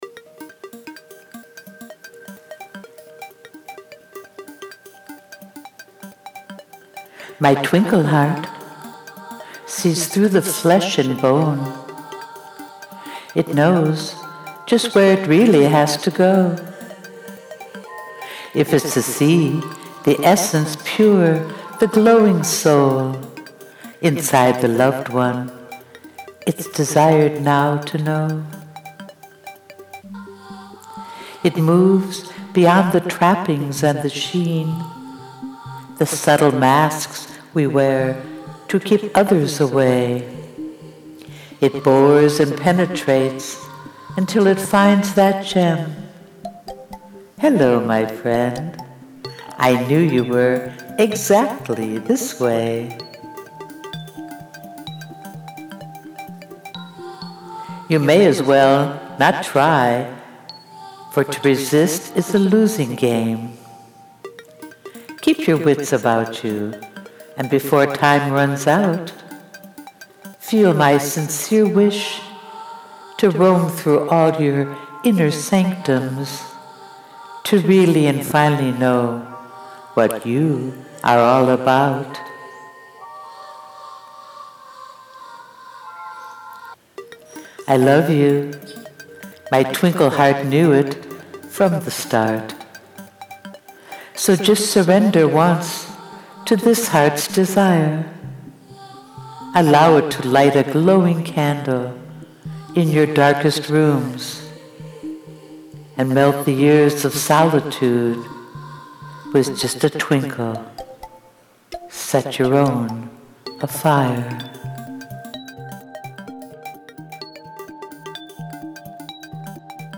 Please Click on Play to hear me recite the poem, “Twinkle Heart” to my own audio track written on my new Garage Band.
I adore the sort of twinkly fae character that you read this in.
And a very twinkly and appropriate music track as well; you are making this new GarageBand your own…